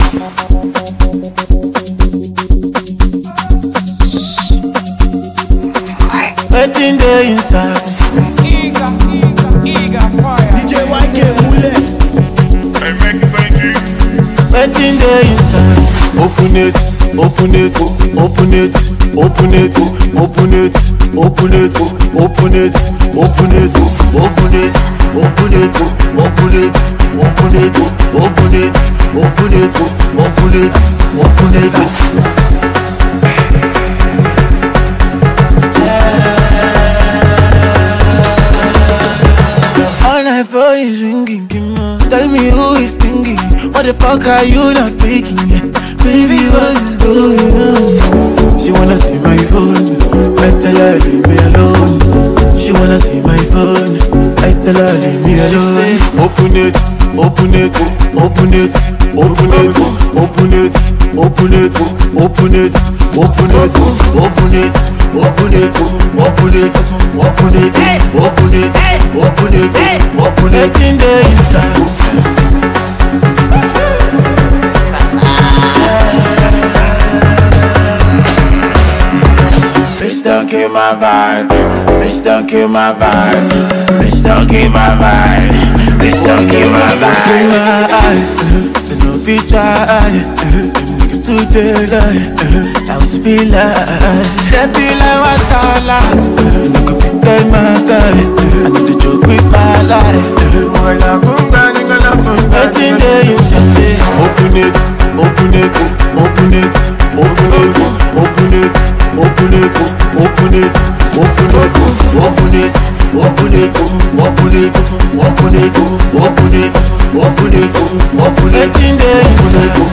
” an enthralling anthem.